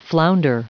Prononciation du mot flounder en anglais (fichier audio)